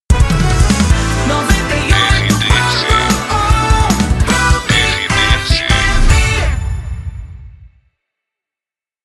Passagem